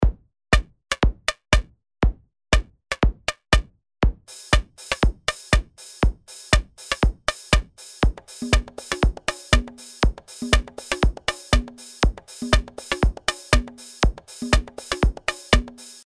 Als nächstes schnappe ich mir die Snare, für die das Modell Punch 27 verwendet wird.
Über die seriell geschalteten Effekte 1 und 2 habe ich einen Pan und den Ringmodulator hinzugezogen.